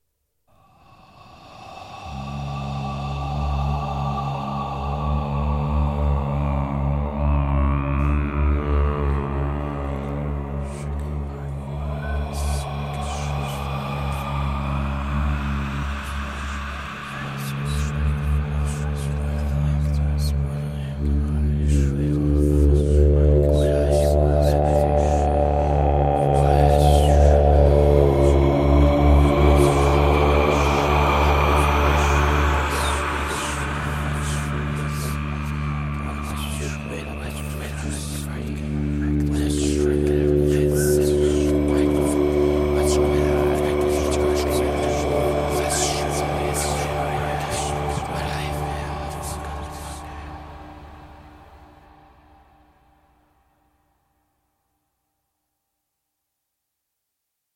От шепота потусторонних сущностей до зловещих завываний – каждый звук перенесёт вас в мир, где реальность теряет границы.
Звук темной магии: как проклясть человека